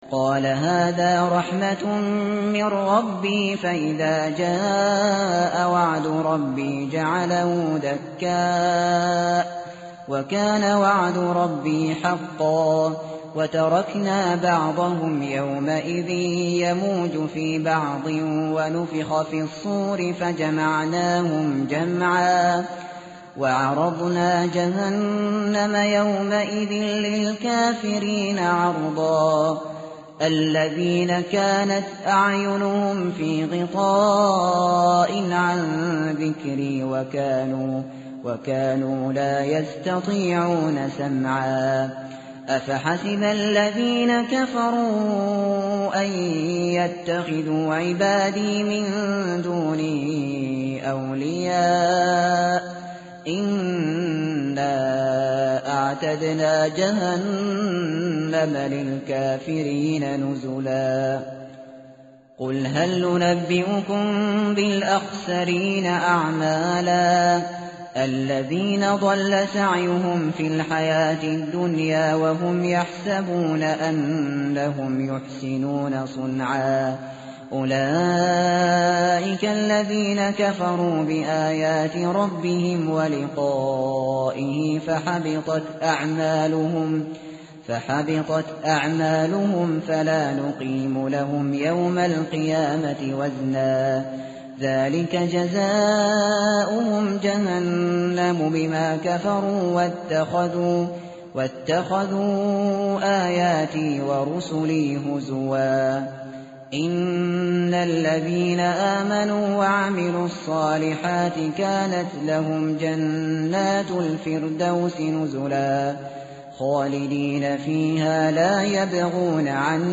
متن قرآن همراه باتلاوت قرآن و ترجمه
tartil_shateri_page_304.mp3